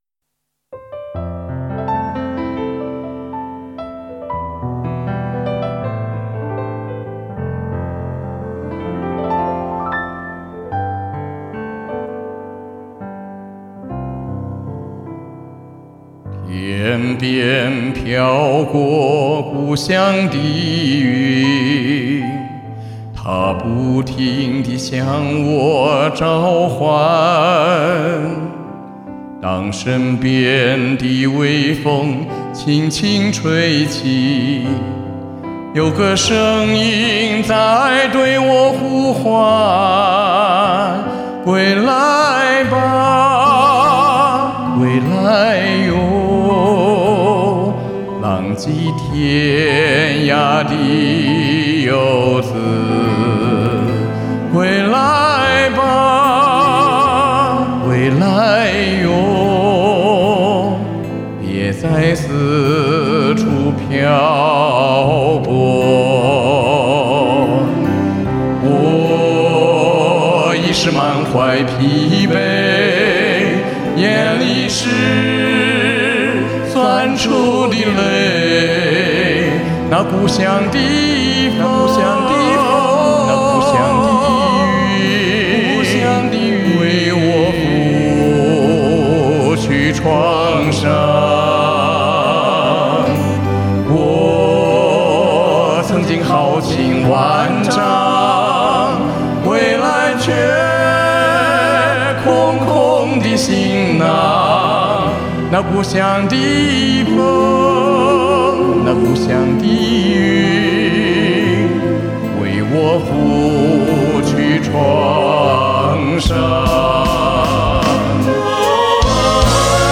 再按合唱模式配上高音的和声。
年过半百人的歌声有了更多漂泊的沧桑和对故乡的刻骨铭心的思念，这是年轻人没法复制的!